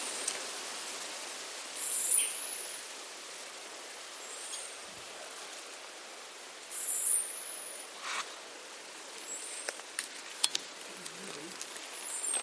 Uniform Finch (Haplospiza unicolor)
el día 6 de noviembre registramos 11 individuos en sotobosque
Sex: Male
Life Stage: Adult
Location or protected area: Valle del Lunarejo
Certainty: Recorded vocal